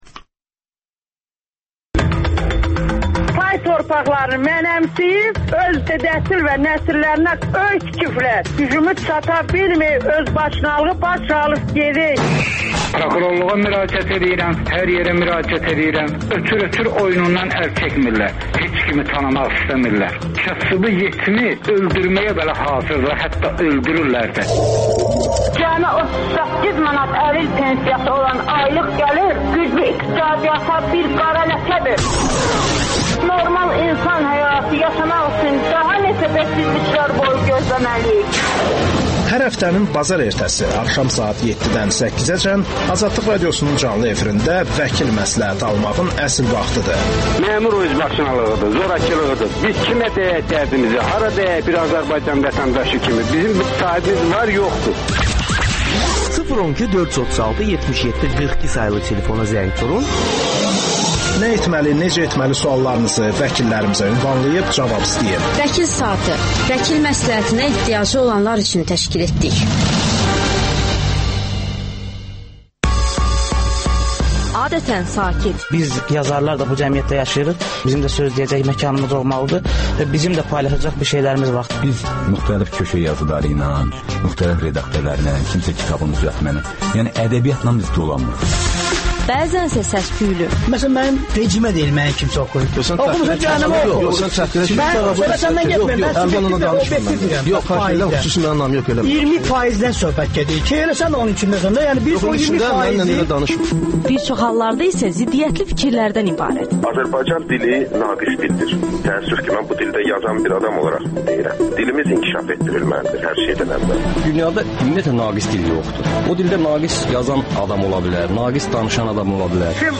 radiodebatı